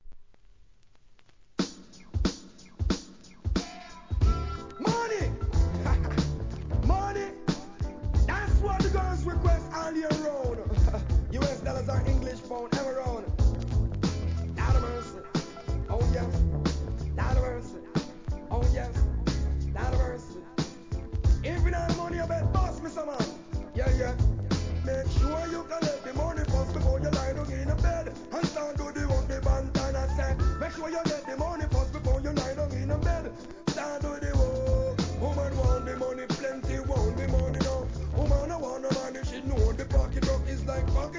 REGGAE
HIP HOPの影響も色濃い1995年アルバム!!